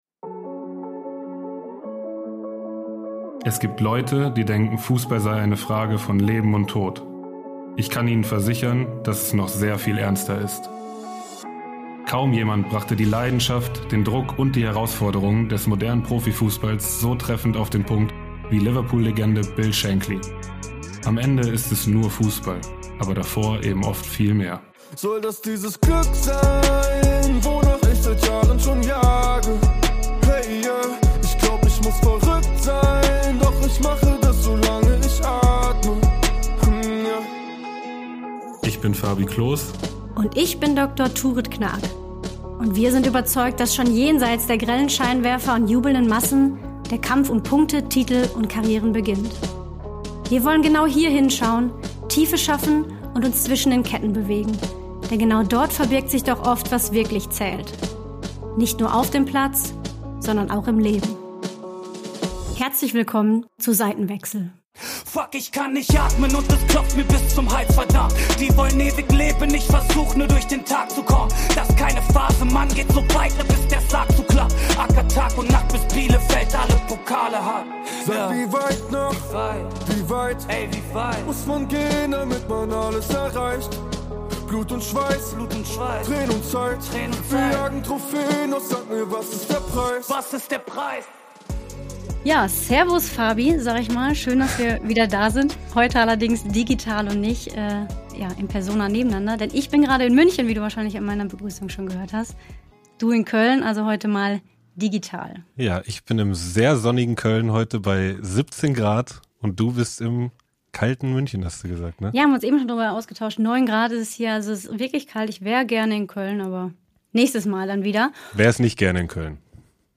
Ex-Nationalspielerin Dr. Turid Knaak und Arminia-Legende Fabi Klos weKKseln die Seiten und sprechen mit (Ex-) Profis, Betroffenen und Expert*innen über die Schattenseiten des Geschäfts: psychische Belastungen, Abstürze, Skandale, massive Anfeindungen und menschliche Tragödien. Aber auch über die Magie dieses Spiels, seinen gesellschaftlichen Impact und die bedingungslose Liebe zum runden Leder.